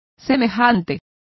Also find out how semejante is pronounced correctly.